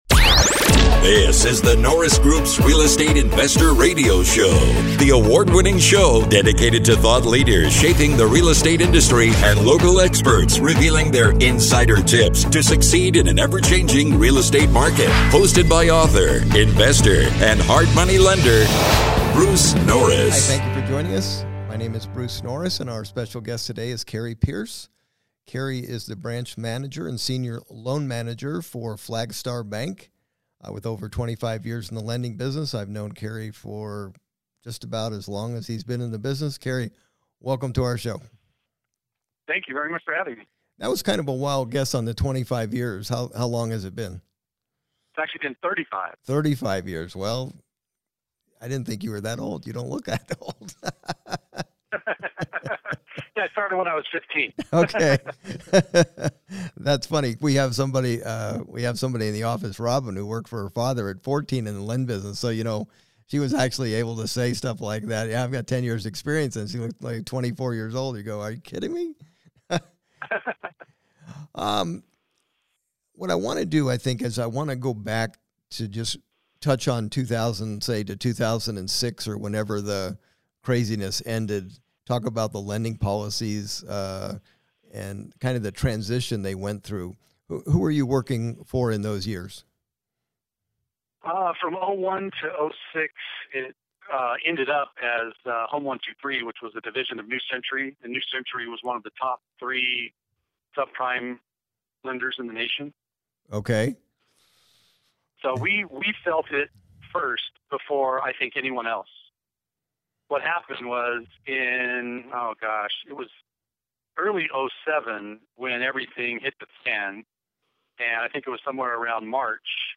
This interview is a follow-up to what they have done and if they still love ADUs.